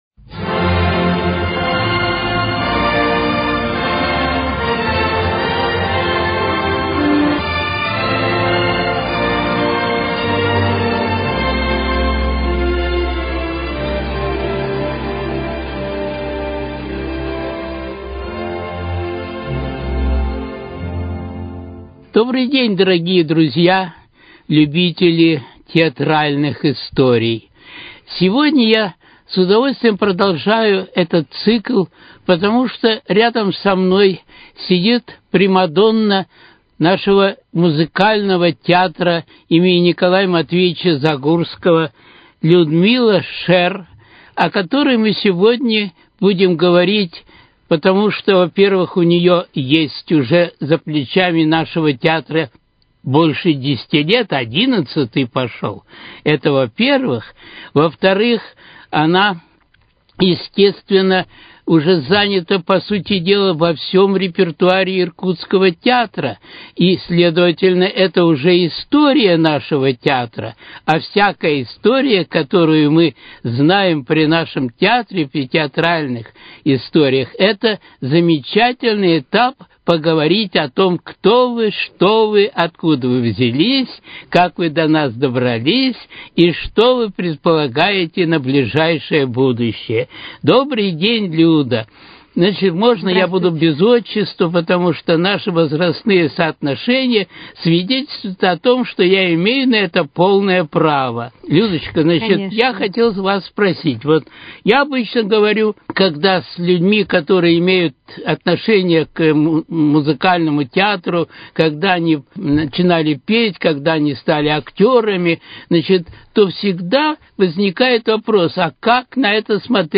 Авторский цикл передач